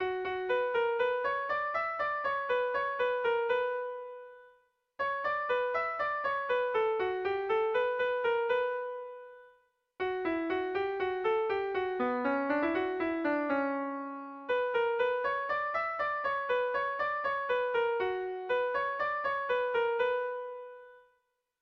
Erlijiozkoa
Zortziko ertaina (hg) / Lau puntuko ertaina (ip)
ABDE